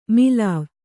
♪ milāv